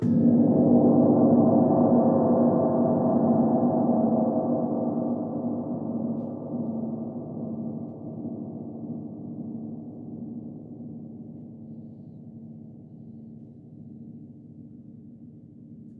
Grandgong_3eme_essaie.wav